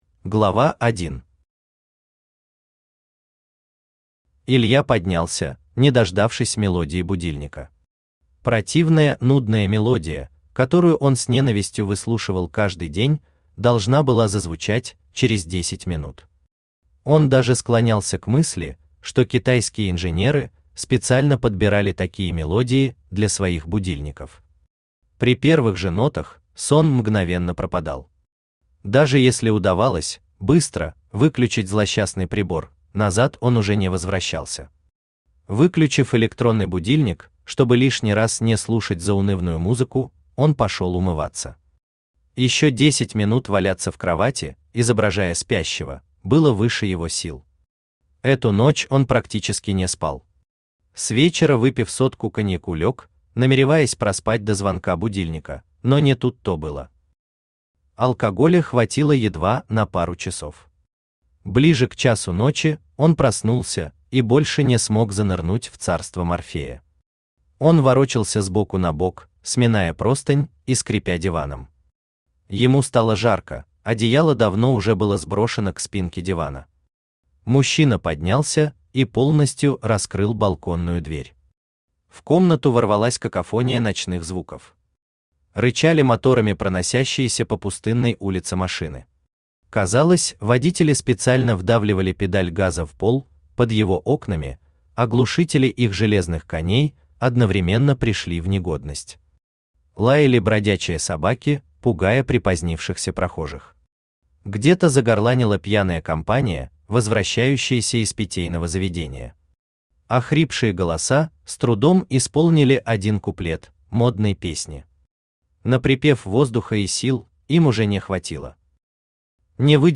Дорогою ушедших поколений Автор Владимир Алексеевич Сергеев Читает аудиокнигу Авточтец ЛитРес.